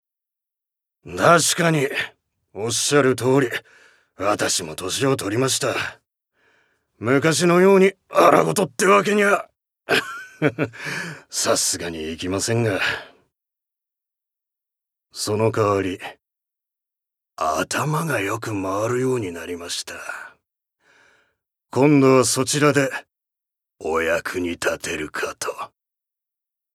Voice Sample
ボイスサンプル
セリフ３